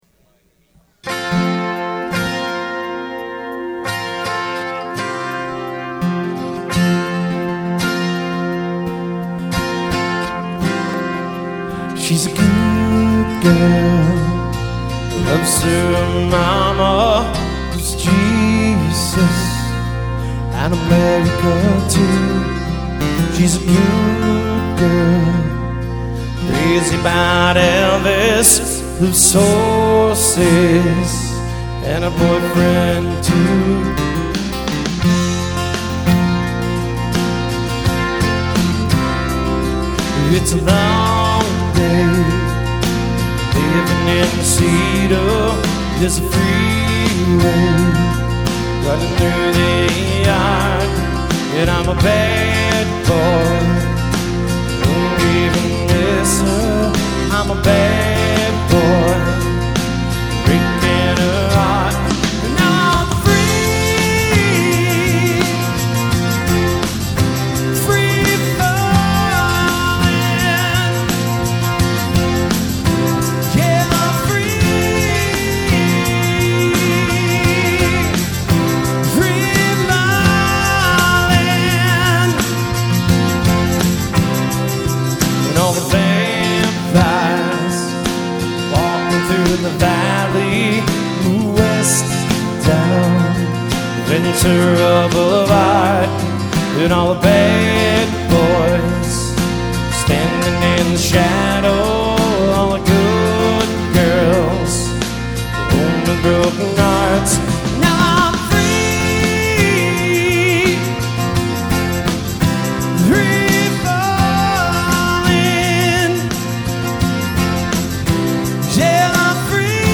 Acoustic/electric guitars and one singer.
(guitar, vocals with backing tracks)